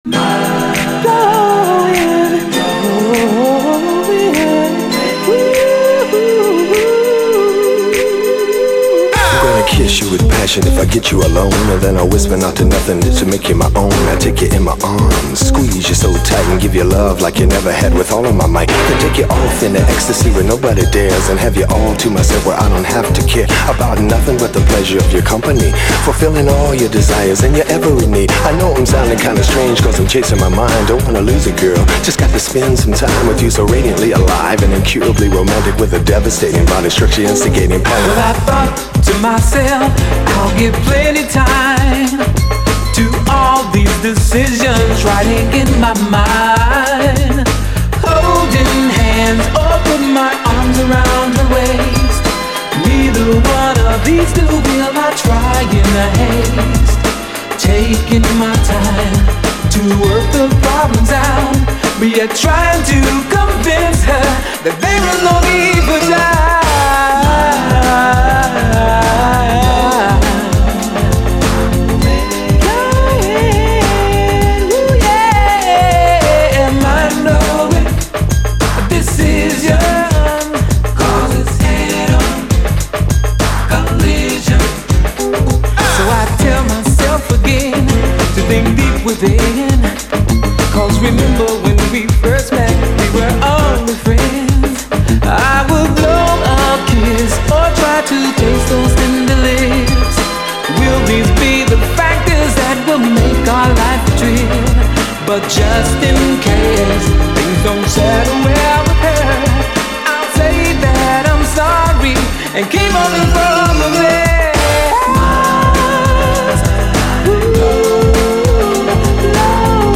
SOUL, 70's～ SOUL, DISCO, HIPHOP, 7INCH
軽やかなシンセと弾むビート、ラップも入って爽やかな90’Sフレイヴァー全開！